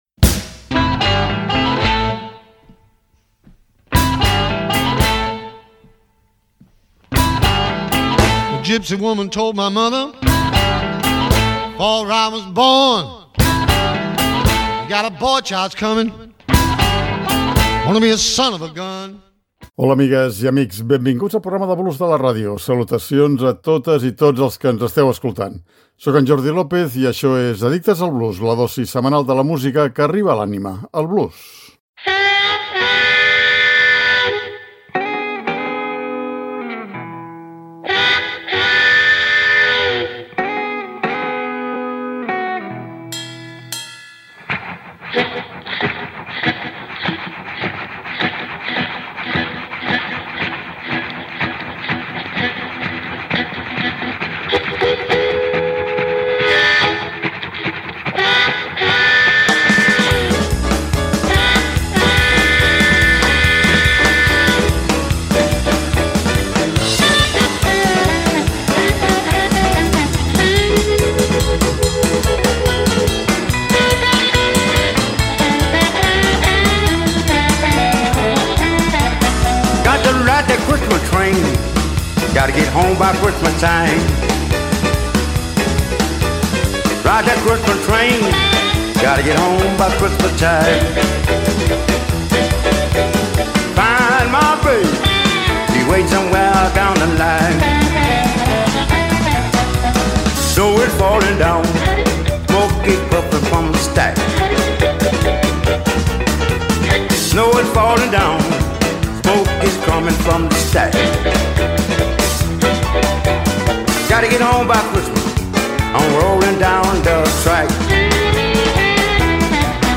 I per això no ens podem estar de dedicar un nou programa amb nadales a ritme de blues, perquè el blues arriba a l’anima però també al cor.